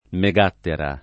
[ me g# ttera ]